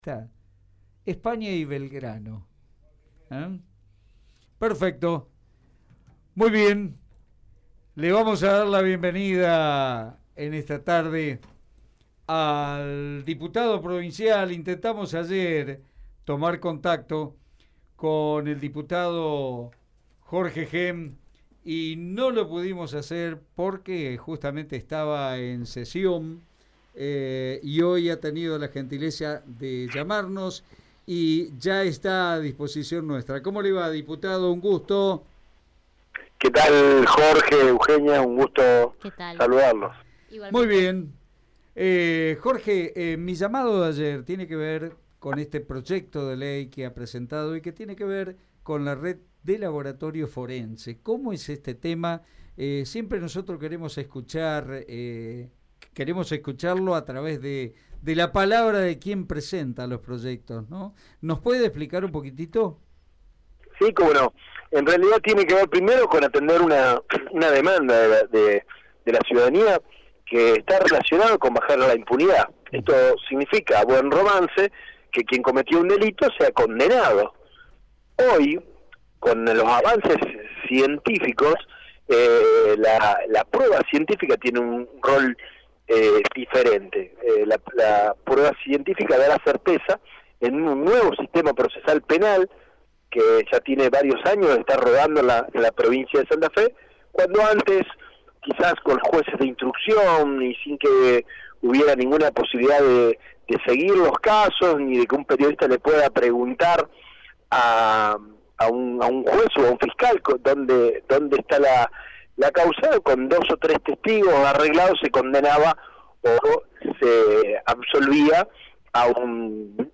Esto lo realizó Jorge Henn y habló con Radio EME para explicar de qué se trata.